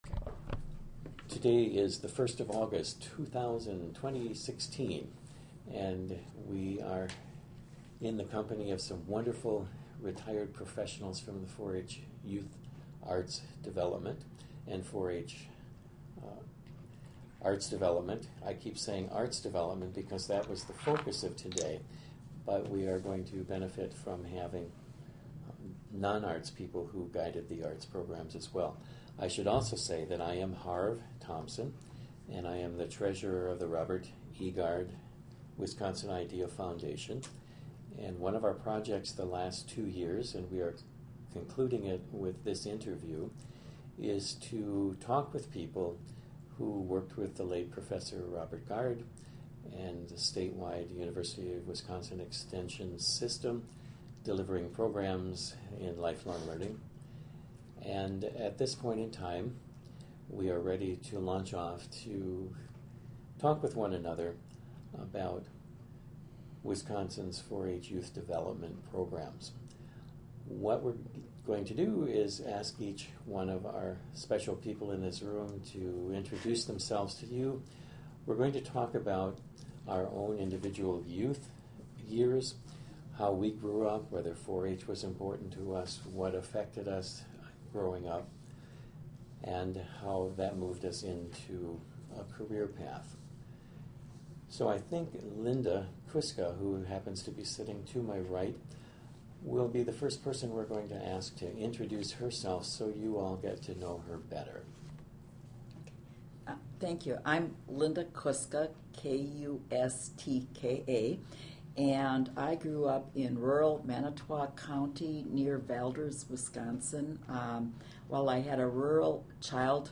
Oral History Interview: 4H Youth Professionals (1566)